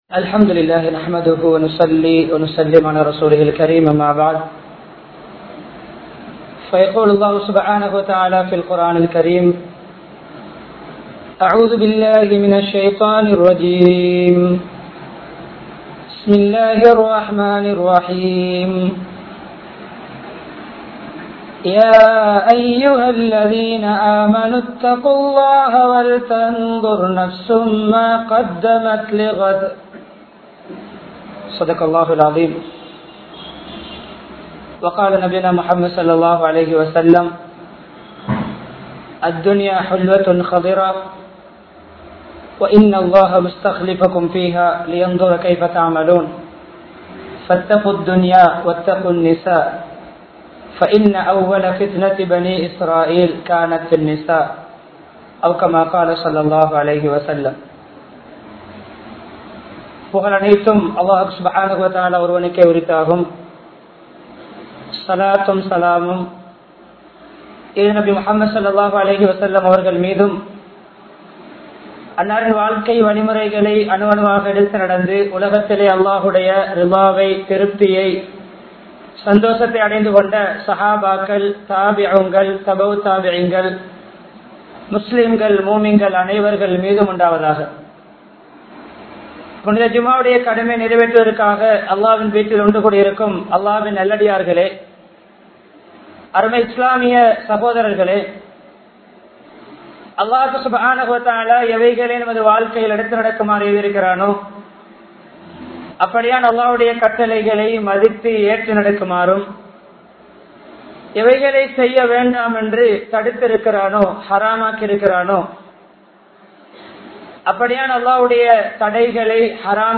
Islamiya Muraippadi Vaalvoam (இஸ்லாமிய முறைப்படி வாழ்வோம்) | Audio Bayans | All Ceylon Muslim Youth Community | Addalaichenai